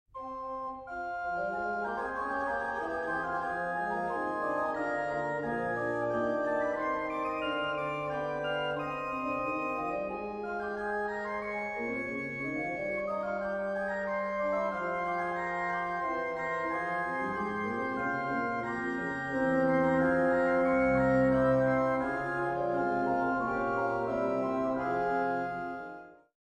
1980 Ahrend organ in Monash University, Melbourne
Organ